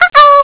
Cŕŕn Zvuk prijatej novej spravy 0:02